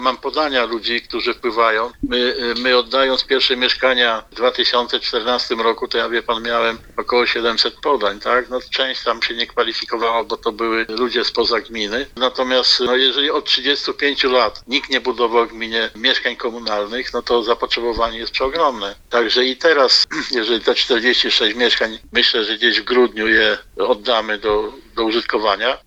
– Mówi Andrzej Wyganowski, burmistrz Stepnicy.